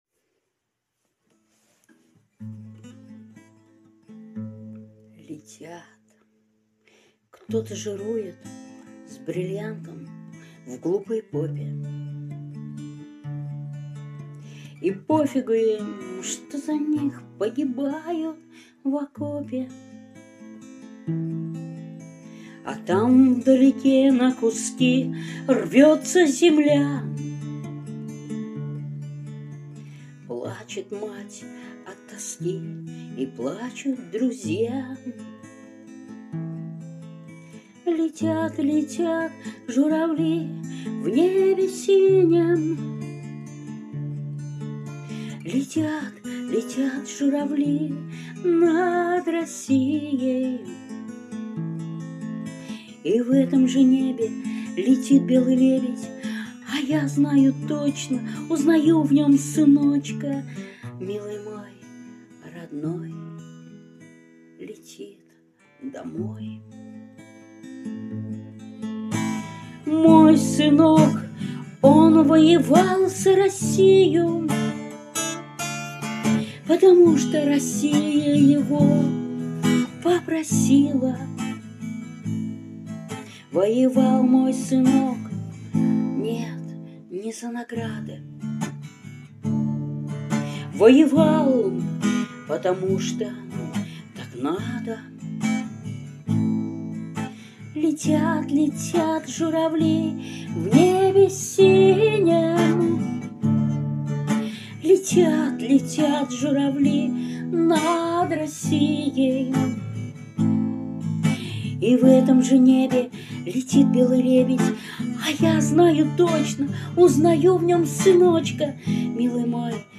Нет артиста - Нет песни 0:00 0:00 0 из 0 Нет песни Нет артиста Запись - Летят (авторская песня) Авторизация Войти Забыли пароль?